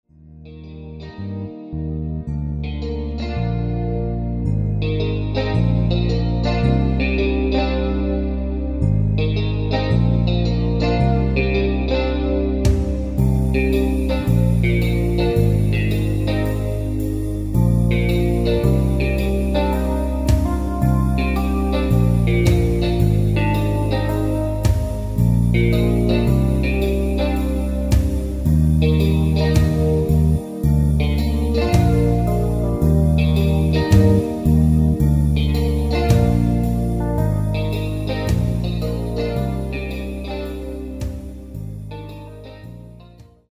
DEMO MP3 MIDI